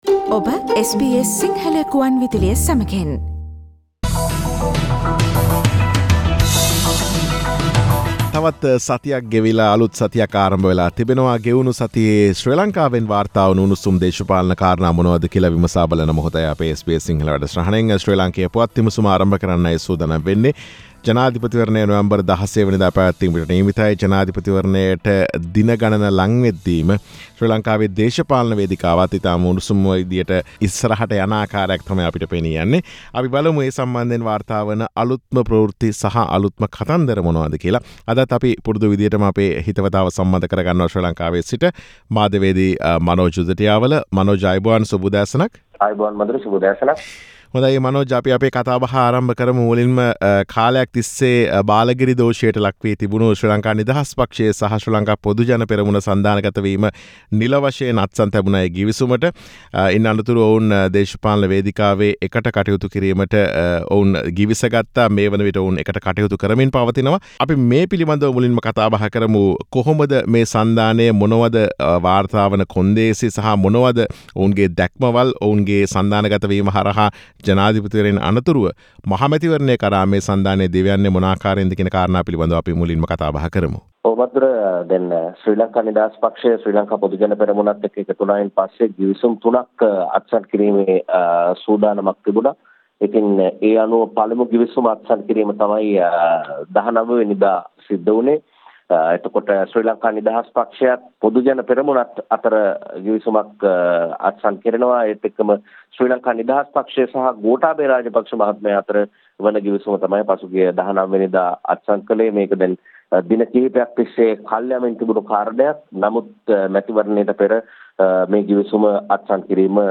සතියේ ශ්‍රී ලාංකේය දේශපාලන පුවත් සමාලෝචනය.